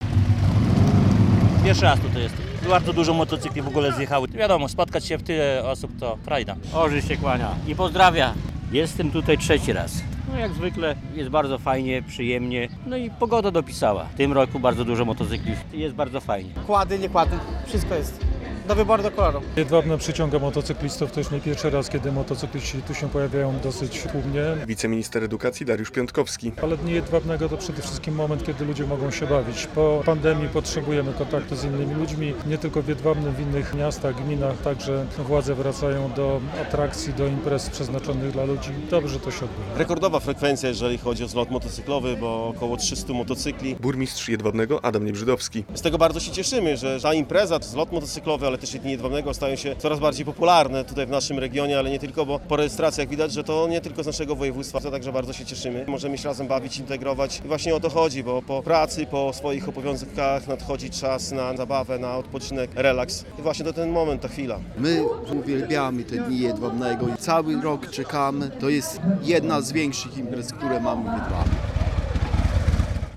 Jedwabne świętuje - parada motocyklistów, festyn i koncerty - relacja